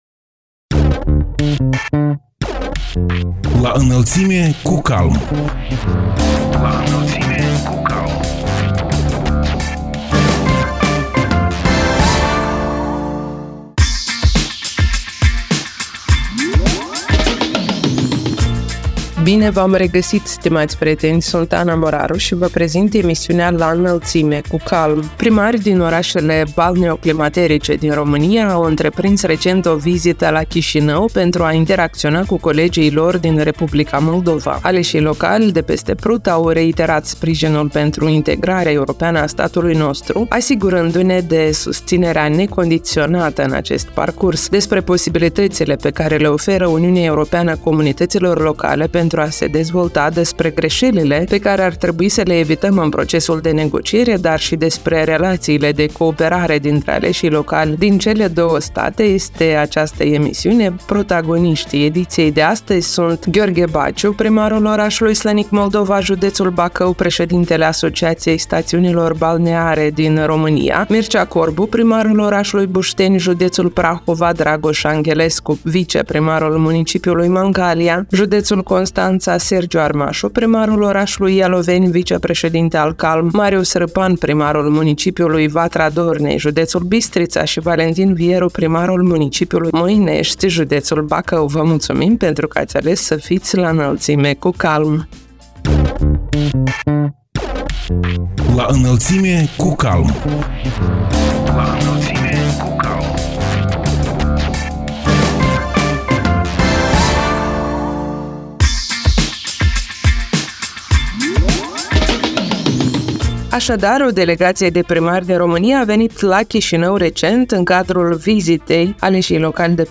Protagoniștii ediției sunt Gheorghe Baciu, primarul orașului Slănic Moldova, județul Bacău; președintele Asociației Stațiunilor Balneare din România; Mircea Corbu, primarul orașului Bușteni, județul Prahova; Dragoș Anghelescu, viceprimarul municipiului Mangalia, județul Constanța; Sergiu Armașu, primarul orașului Ialoveni, vicepreședinte al CALM; Marius Rîpan, primarul municipiului Vatra Dornei, județul Suceava și Valentin Vieru, primarul municipiului Moinești, județul Bacău.